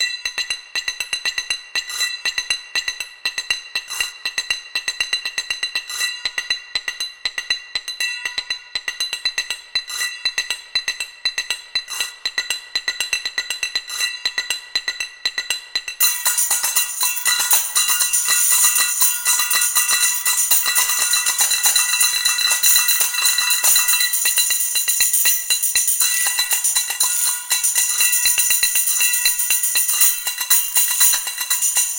Für die Klangbeispiele habe ich verschiedene MIDI Dateien mit den entsprechenden Instrumenten aufgenommen.
Die Klänge der einzelnen Instrumente sind authentisch und mit Hilfe der vorhandenen MIDI Dateien können schnell tolle Rhythmen erstellt werden.
arabic-percussion-3.mp3